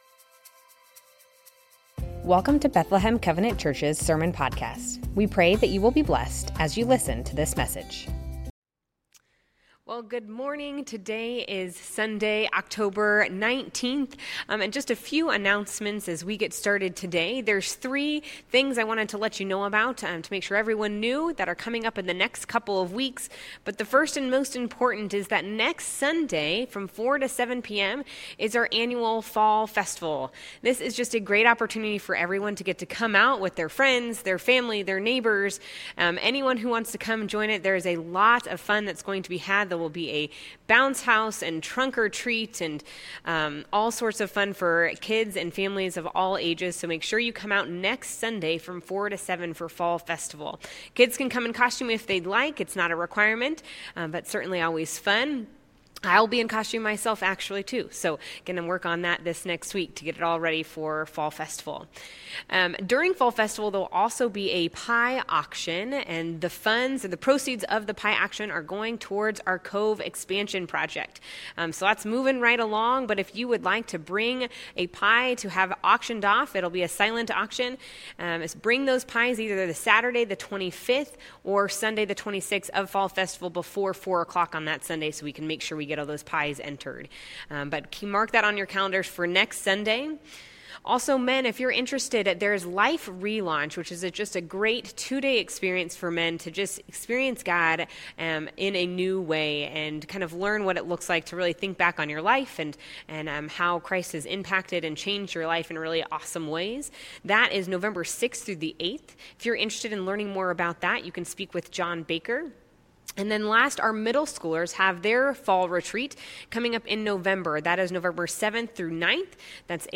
Bethlehem Covenant Church Sermons The Names of God - Jehovah Shalom Oct 19 2025 | 00:29:59 1x 00:00 / 00:29:59 Subscribe Share Spotify RSS Feed Share Link Embed